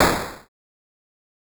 8bit Noise
8bit_FX_noise_01_01.wav